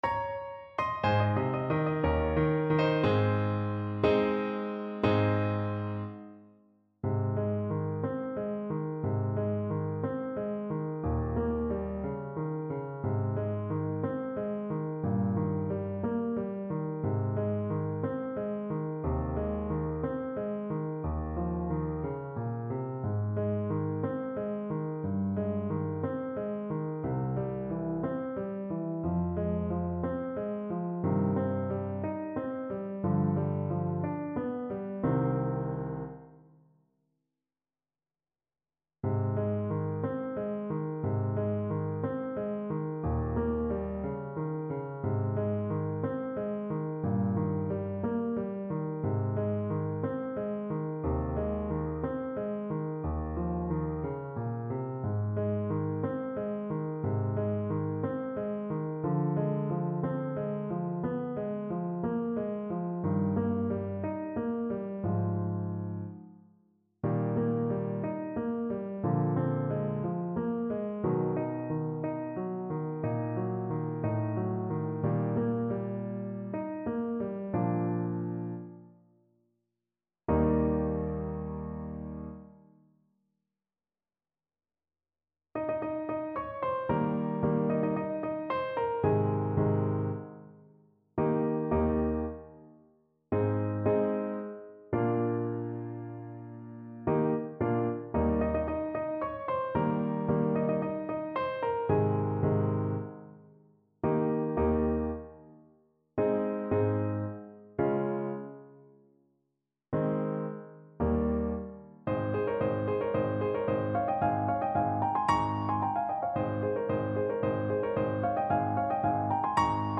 Play (or use space bar on your keyboard) Pause Music Playalong - Piano Accompaniment Playalong Band Accompaniment not yet available transpose reset tempo print settings full screen
Alto Saxophone
Andante =c.60
Ab major (Sounding Pitch) F major (Alto Saxophone in Eb) (View more Ab major Music for Saxophone )
2/4 (View more 2/4 Music)
Classical (View more Classical Saxophone Music)
rossini_ecco_ridente_ASAX_kar1.mp3